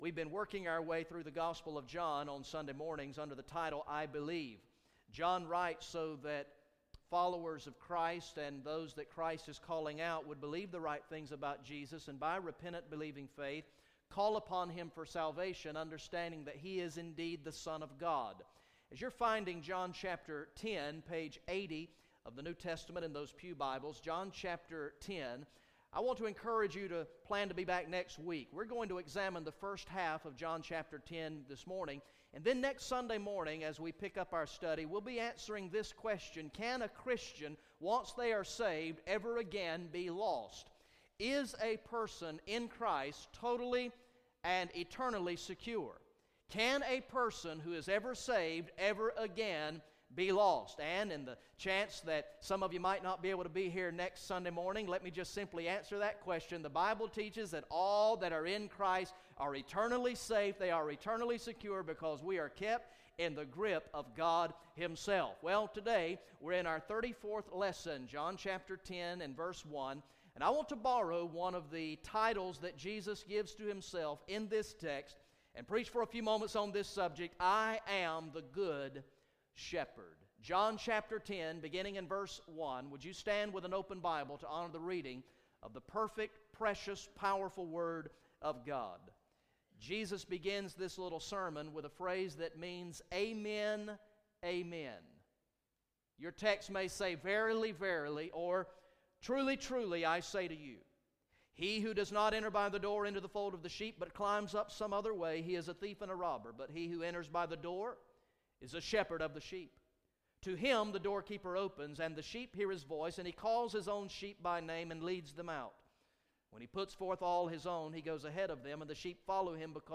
Message #34 from the sermon series through the gospel of John entitled "I Believe" Recorded in the morning worship service on Sunday, March 1, 2015